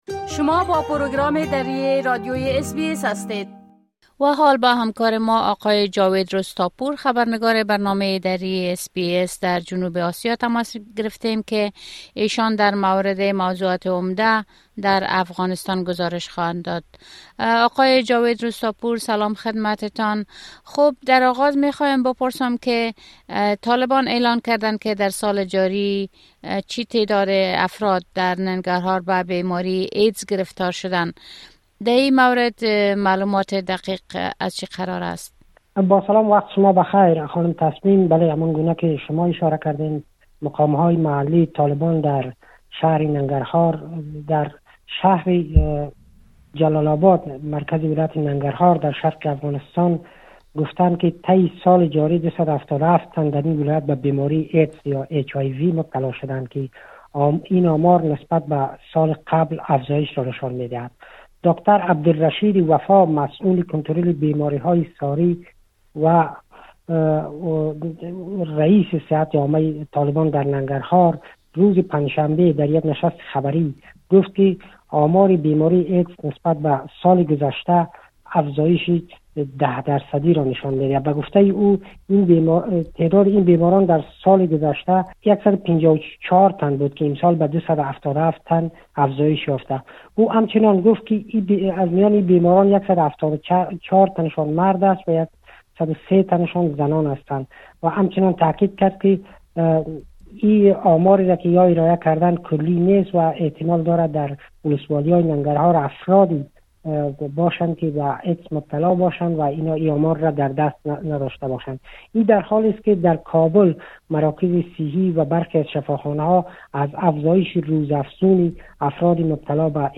مقام‌های محلی طالبان در شرق افغانستان از ابتلای صدها نفر باشنده ولایت ننگرهار به بیماری ایدز خبر داده‌اند. گزارش کامل خبرنگار محلی ما از اوضاع امنیتی و تحولات متهم دیگر افغانستان را این‌جا بشنوید.